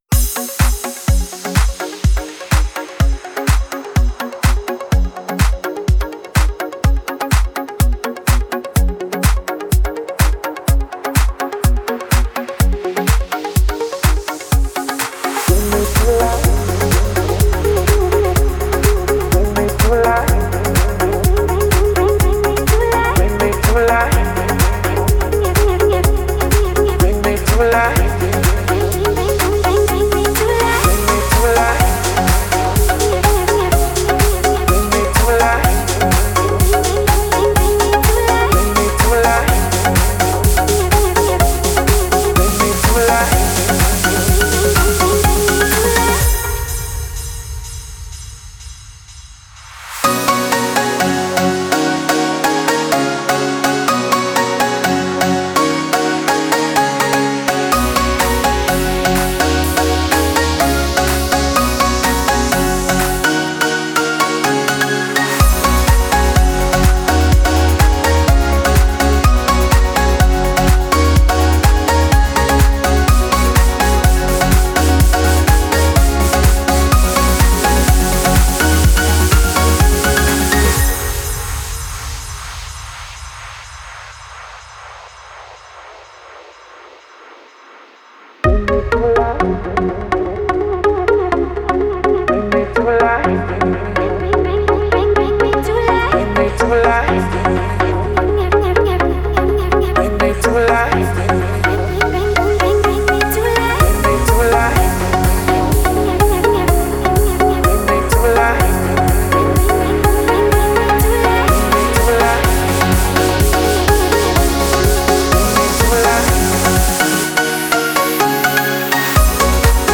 Стиль: Dance / Pop